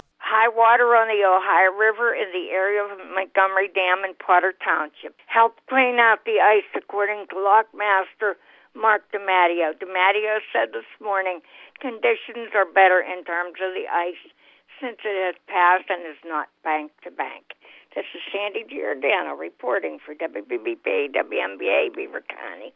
OF THE 27 BARGES THAT WERE DISLODGED SATURDAY MORNING NEAR THE EMSWORTH LOCK AND DAM ON THE OHIO RIVER, TWO REMAINED UNACCOUNTED FOR…SO THE SITUATION IS IMPROVING. BEAVER COUNTY RADIO NEWS CORRESPONDENT